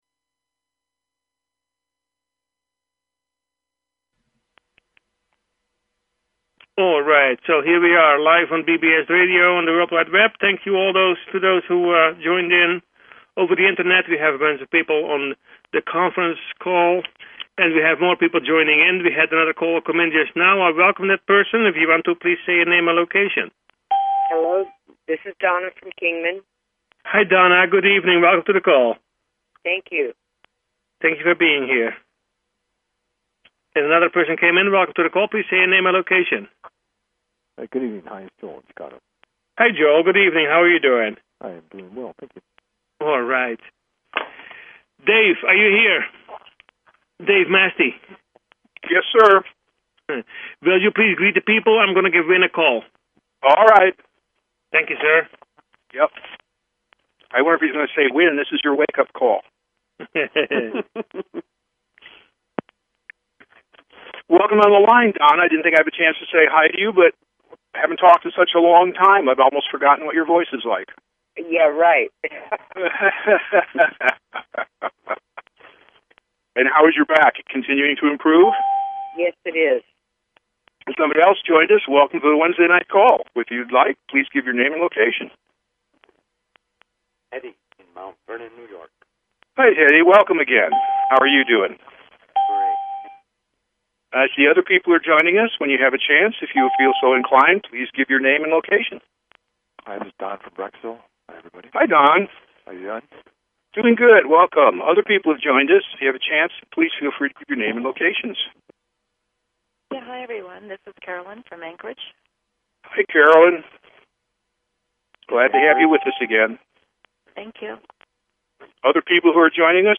Talk Show Episode, Audio Podcast, You_Got_Questions_We_Got_Answers and Courtesy of BBS Radio on , show guests , about , categorized as